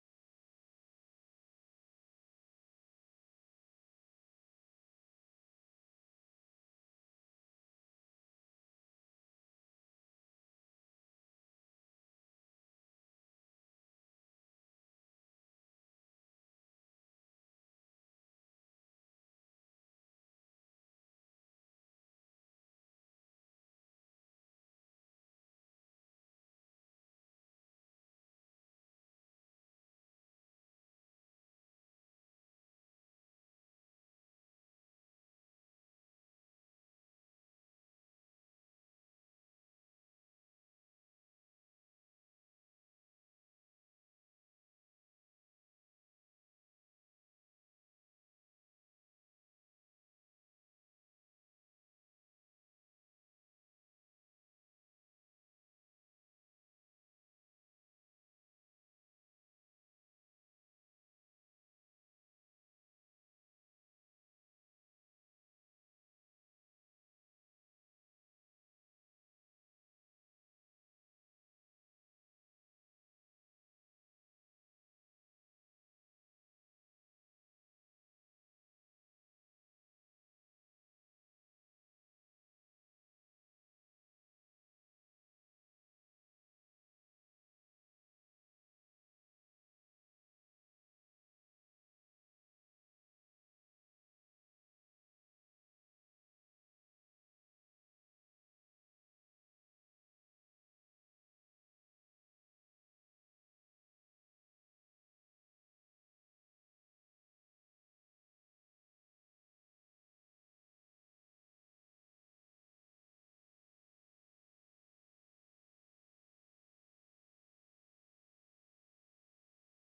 It's not the best one, and it's all glitchy and whatnot, but here it is.